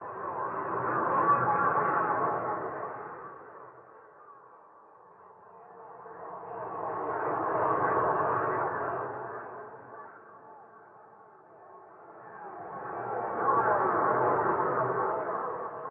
psy_voices.ogg